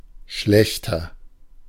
Ääntäminen
Ääntäminen Tuntematon aksentti: IPA: /ˈʃlɛçtɐ/ Haettu sana löytyi näillä lähdekielillä: saksa Käännös 1. peor Schlechter on sanan schlecht komparatiivi.